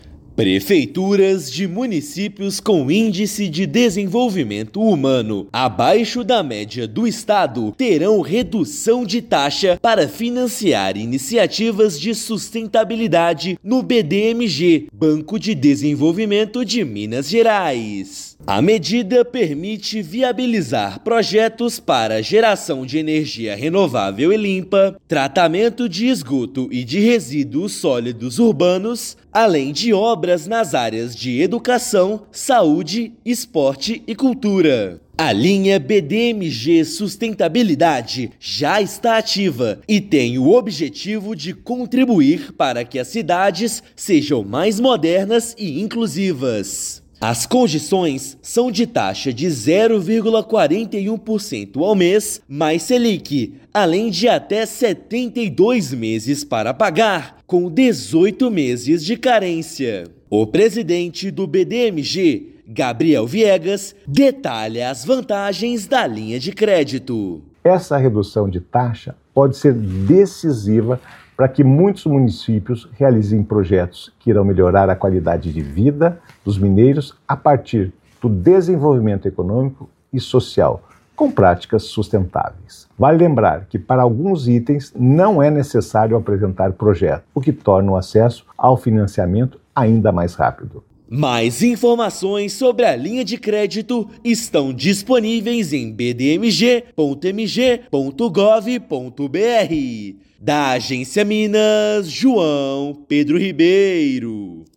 Iniciativa do Banco de Desenvolvimento de Minas Gerais contribui para que prefeituras invistam em obras que geram qualidade de vida à população. Ouça matéria de rádio.